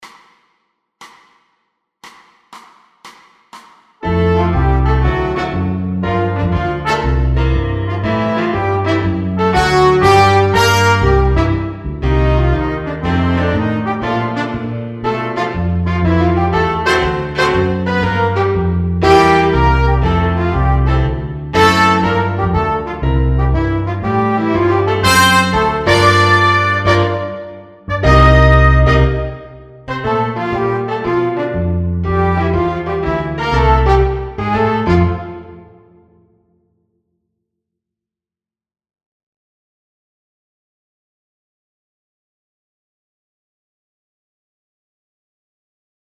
MS Jazz Swing Etude No Drums.mp3